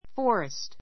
fɔ́ːrist ふォ ーレ スト ｜ fɔ́rist ふォ レ スト